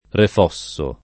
refosso [ ref 0SS o ]